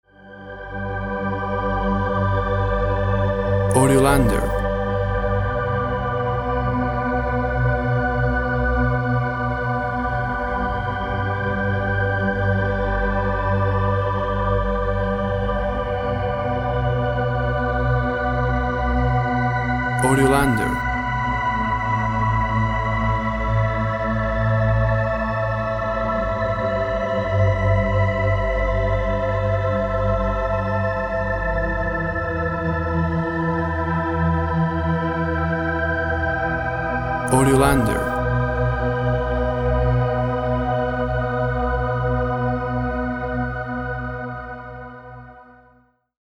Synth plays sad and hopeful theme.
Tempo (BPM) 48